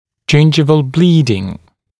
[‘ʤɪnʤɪvəl ‘bliːdɪŋ] [ʤɪn’ʤaɪvəl][‘джиндживэл ‘бли:дин] [джин’джайвэл]кровотечение десны